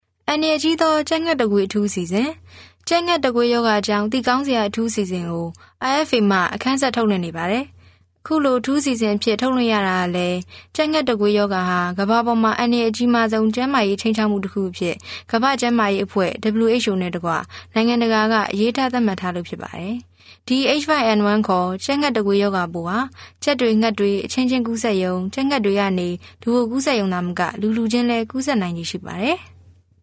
Sprecherin burmesisch für Werbung, TV, Radio, Industriefilme und Podcasts.
Kein Dialekt
Professional female voice over artist from Myanmar.